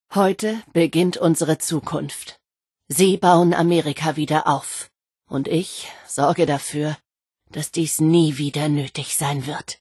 Wastelanders: Audiodialoge